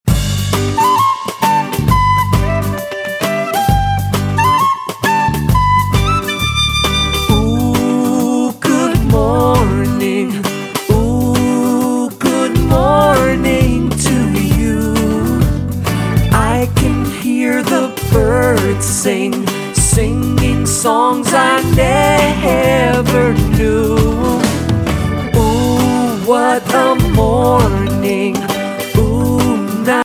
The songs are joyful, optimistic, and lots of fun.